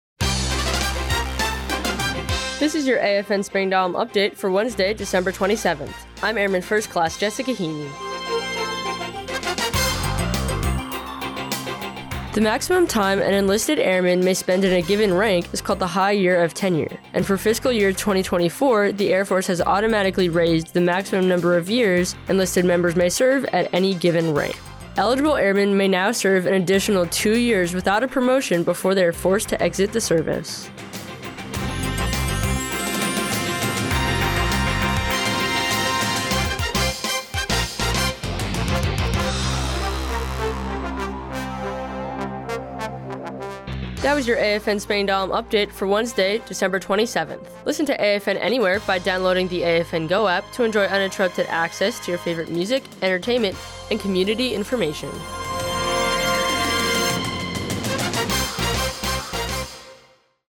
The following was the radio news report for AFN...